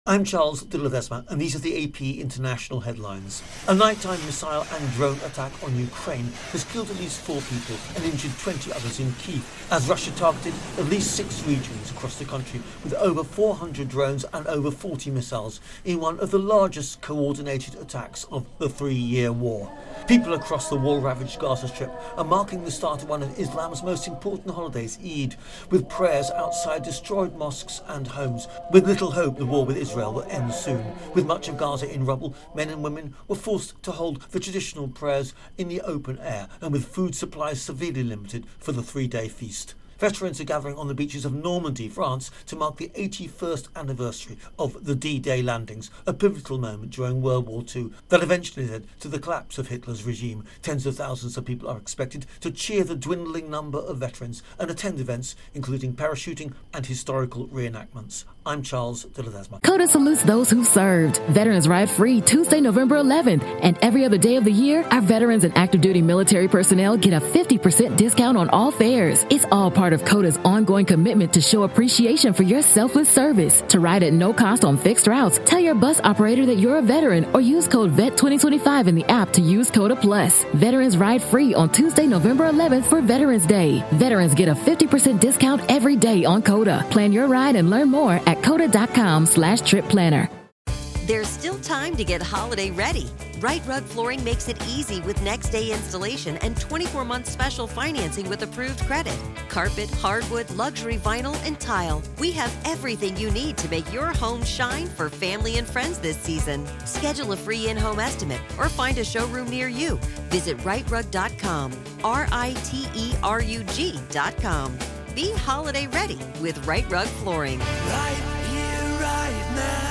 The latest international headlines